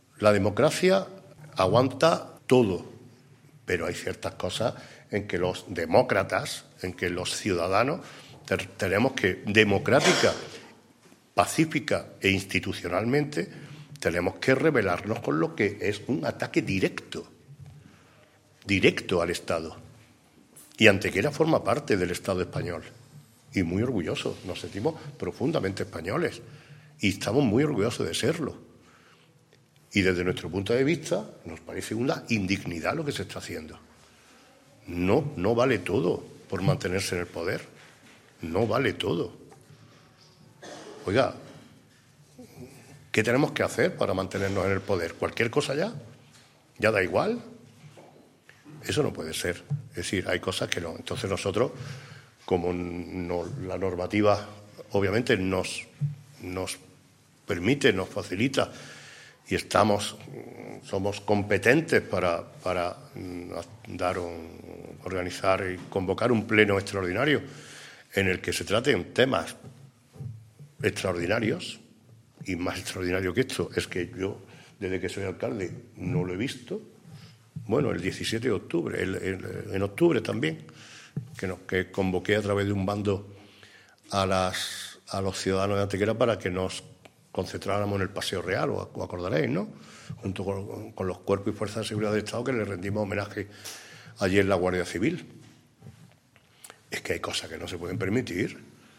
Preguntado en rueda de prensa por la convocatoria de este Pleno, el Alcalde ha justificado la misma “porque Antequera y todos los ayuntamientos forman parte del Estado Español, y ahora se está tratando de romper el Estado Democrático y de Derecho, y cómo no vamos a debatir y rebelarnos democrática y pacíficamente contra eso, para elevarlo a las altas instancias y decir que no estamos de acuerdo con esta nueva forma de estado despótico que se trata de implantar”.
Cortes de voz